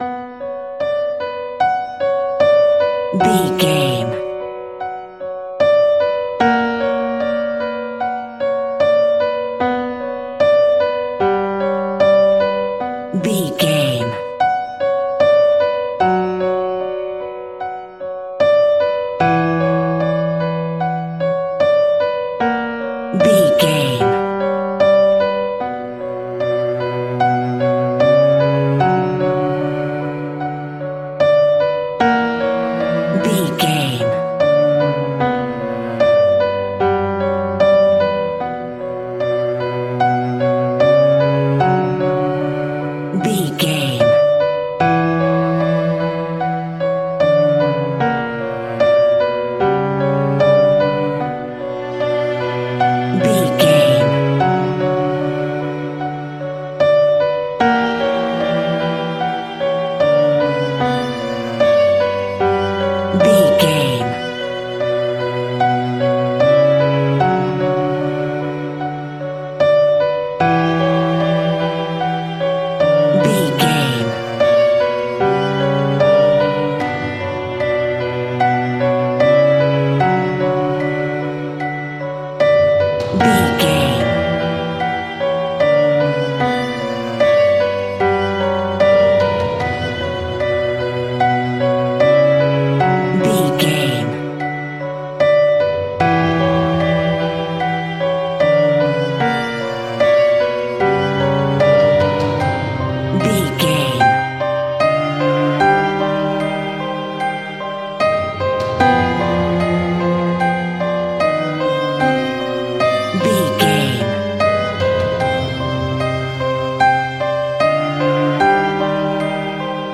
Scary Haunting Music Theme.
Aeolian/Minor
ominous
eerie
piano
creepy
horror music
Horror Pads
Horror Synths